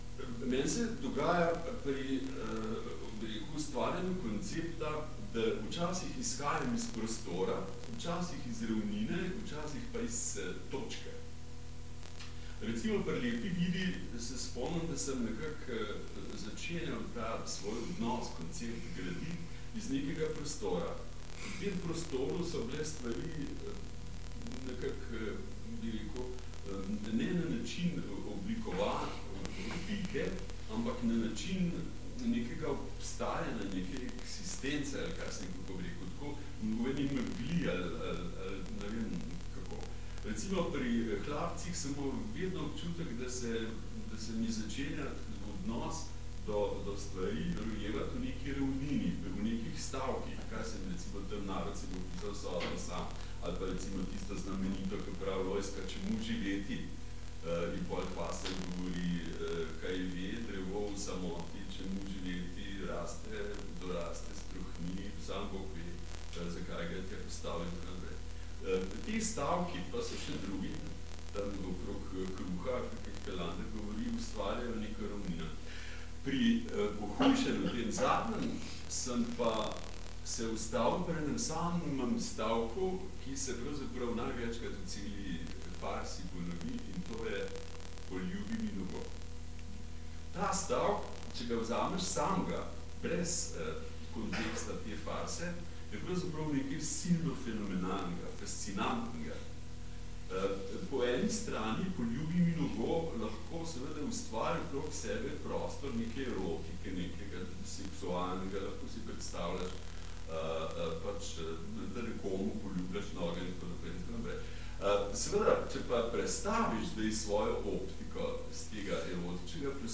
Odlomek iz posnetka, ki je zabeležil dogodek v organizaciji DGKTS.